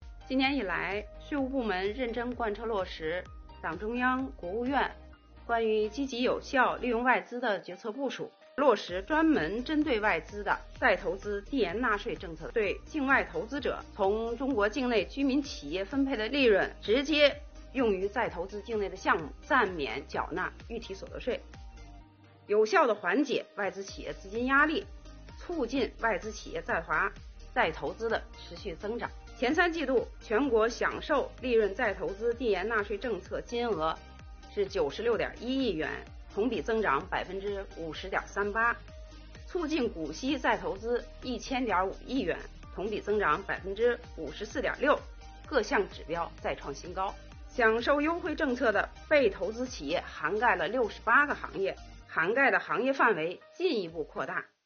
近日，国务院新闻办公室举行国务院政策例行吹风会，国家税务总局相关负责人介绍制造业中小微企业缓税政策等有关情况，并答记者问。
国家税务总局国际税务司司长蒙玉英表示，今年以来，税务部门认真贯彻落实党中央、国务院关于积极有效利用外资的决策部署，落细减税降费优惠政策，更好服务构建以国内大循环为主体、国内国际双循环相互促进的新发展格局。